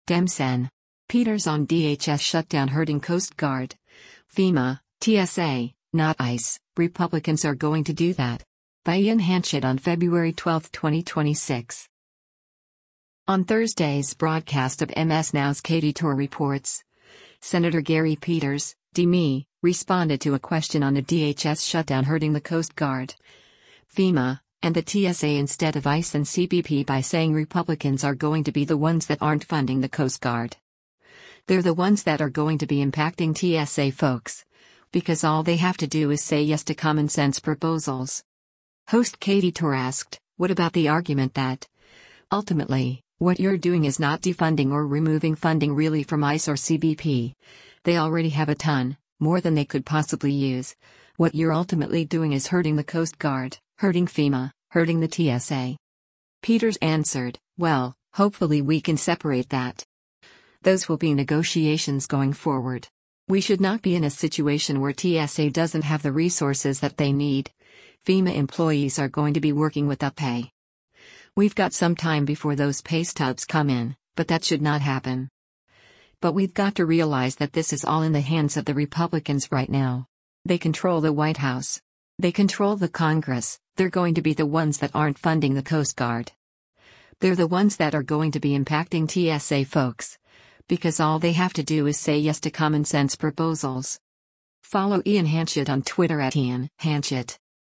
On Thursday’s broadcast of MS NOW’s “Katy Tur Reports,” Sen. Gary Peters (D-MI) responded to a question on a DHS shutdown hurting the Coast Guard, FEMA, and the TSA instead of ICE and CBP by saying Republicans are “going to be the ones that aren’t funding the Coast Guard.